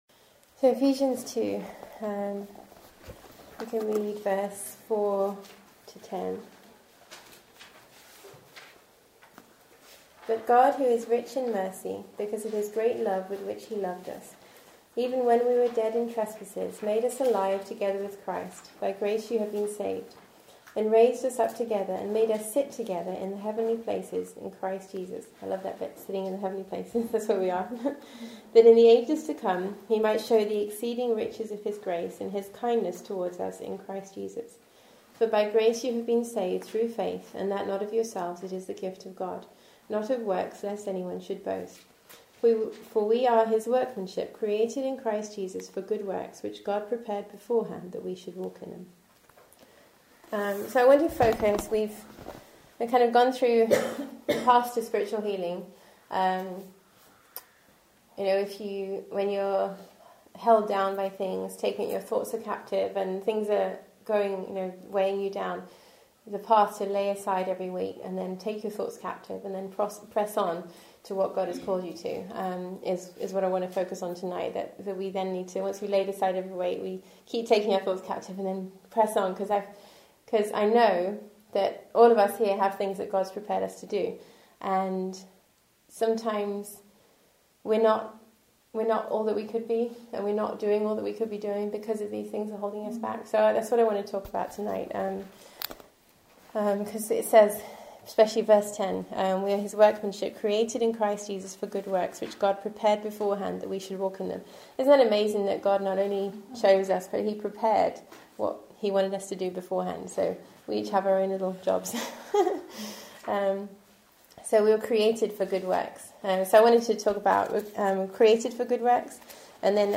Part 3 of a series taught at Pillar Community Church Women’s Retreat in 2013.